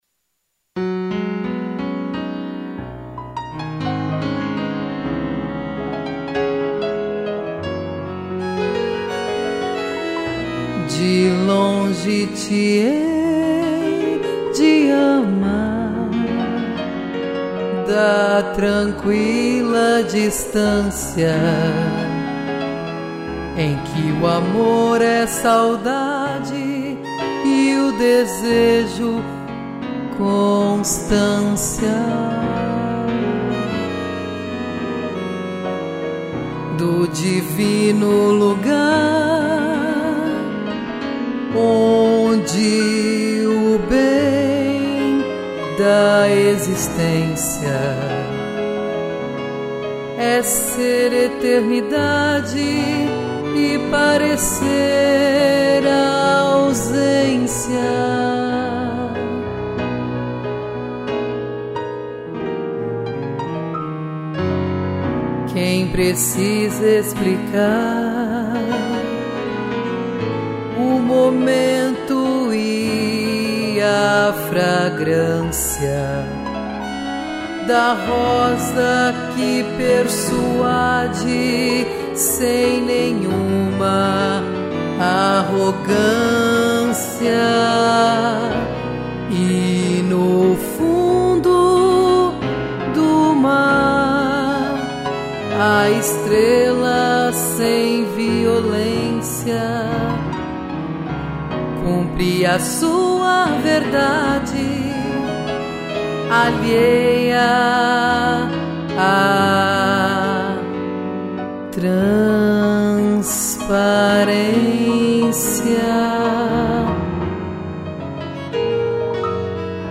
2 pianos, cello e violino